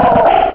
Cri de Phogleur dans Pokémon Rubis et Saphir.